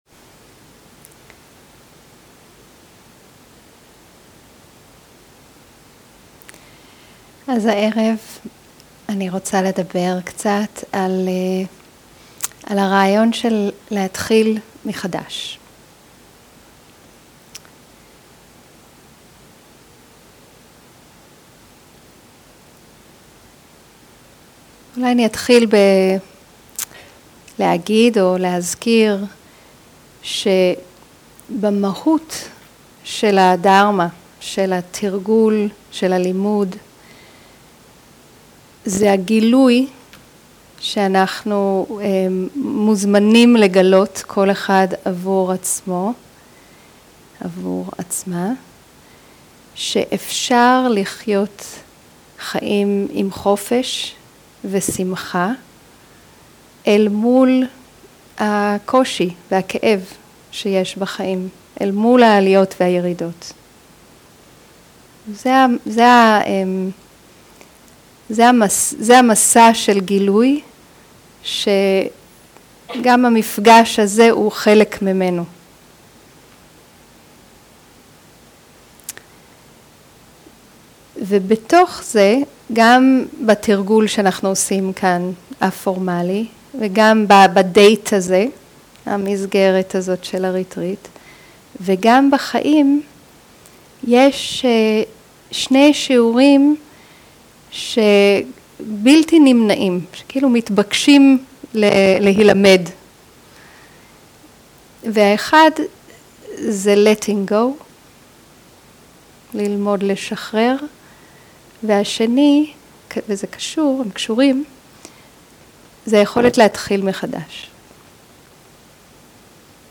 Home › Library › Audio library לשחרר ולהתחיל מחדש לשחרר ולהתחיל מחדש 0:00 0:00 סוג ההקלטה: Dharma type: Dharma Talks שפת ההקלטה: Dharma talk language: Hebrew